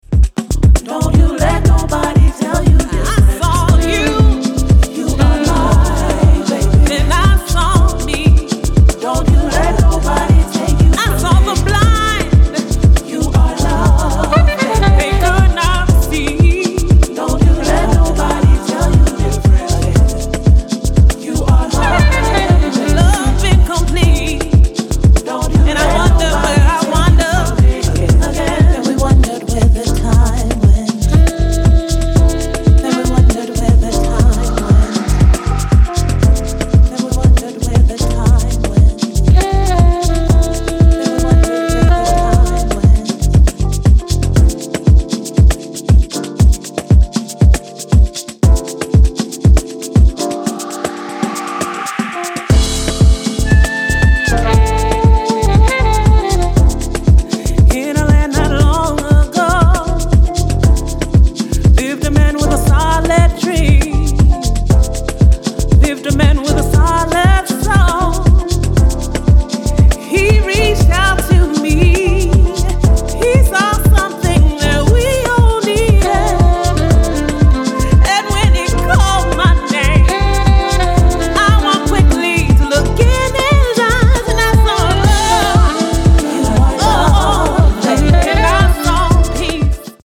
ジャジーなサックスも交えながらしっとりディープ・ウォームなヴォーカル・ハウスを展開しています。